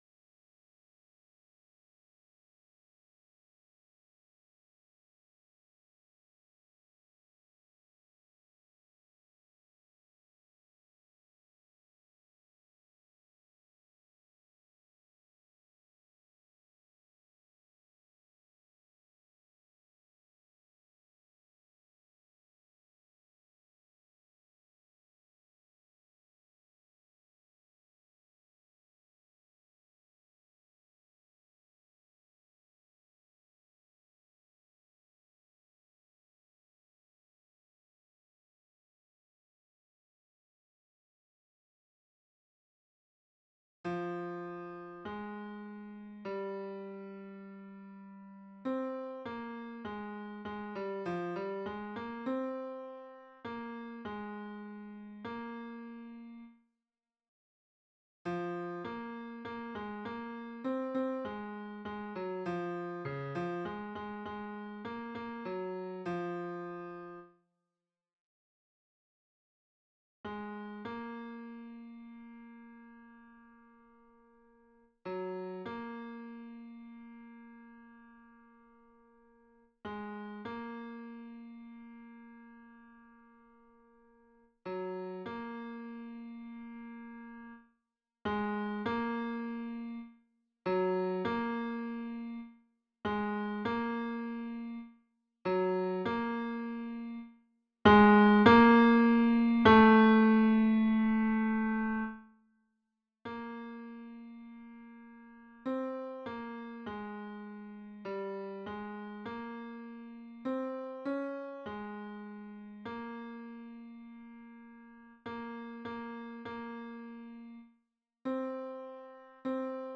Tenor (version piano)